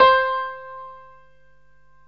PIANO 0015.wav